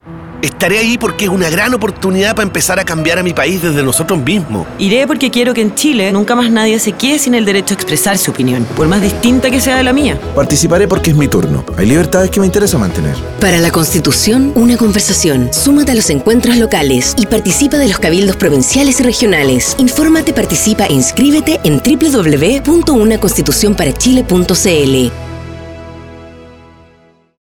Audio con distintas voces testimoniales que llaman a la participación en el proceso constituyente, especialmente en los encuentros locales, cabildos provinciales y regionales 8.